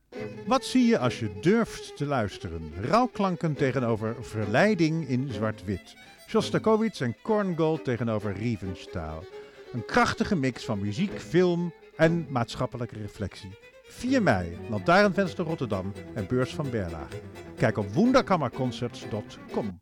Reclamespot op NPO Klassiek
Over propaganda gesproken: Vanaf zondag 20 april is onze reclamespot voor de Project Parallel Truths-concerten op 4 mei twee weken lang, zeven keer per dag te horen op NPO Klassiek. Deze is prachtig voor ons ingesproken door niemand minder dan de bekende cabaretier en podcastmaker Vincent Bijlo!